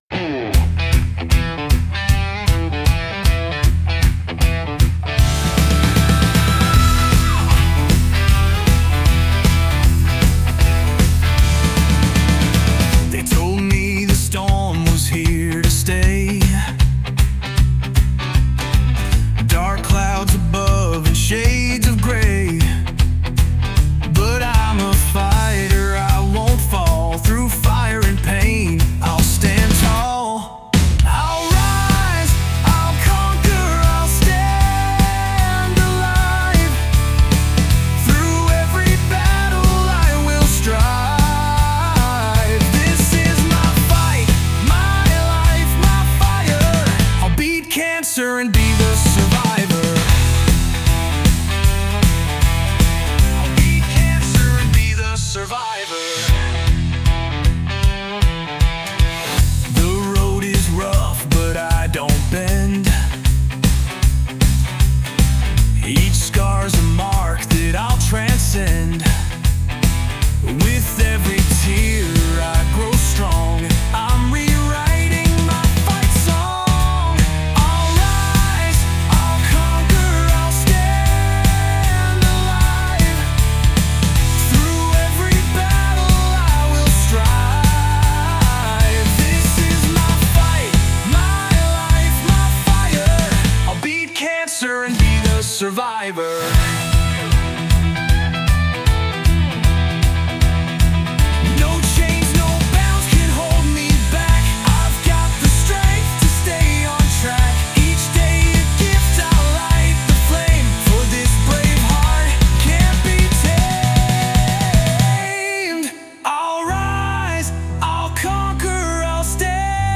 by Mac Productions | Rise and Conquer